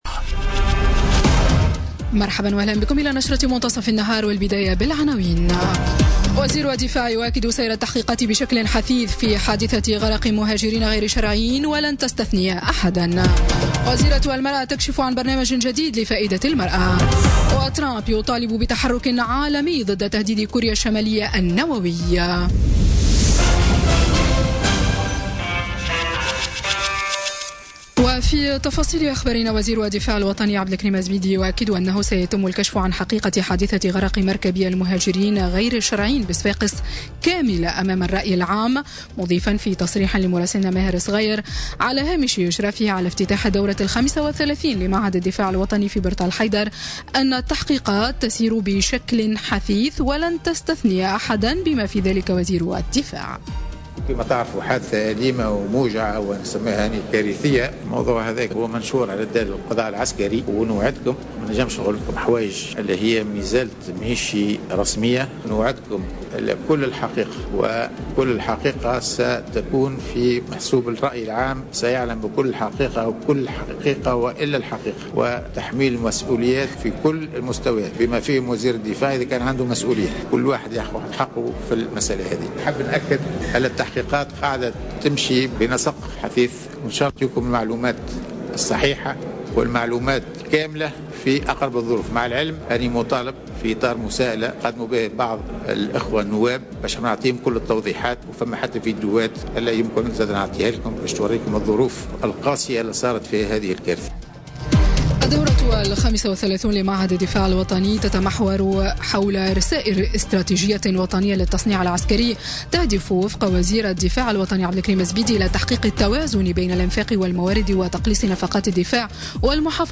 نشرة أخبار منتصف النهار ليوم الثلاثاء 07 نوفمبر 2017